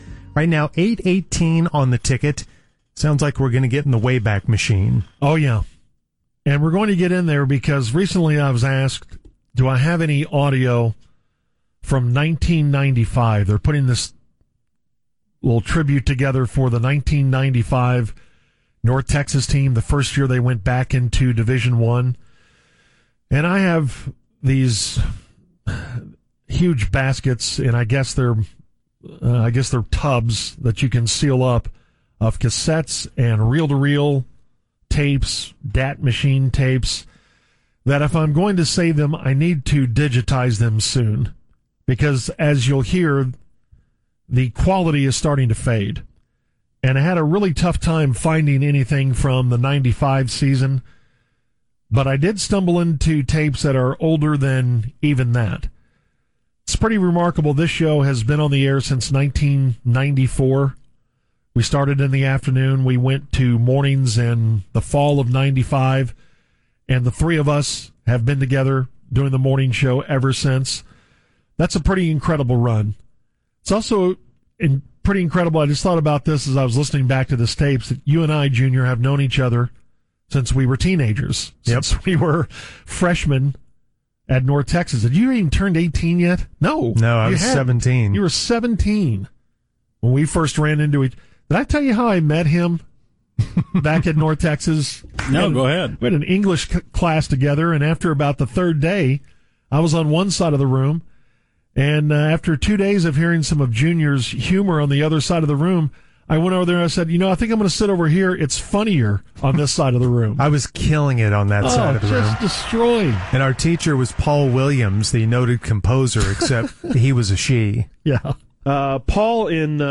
calling play by play for a Wilmer Hutchins game
with some verrrry edgy sideline reporting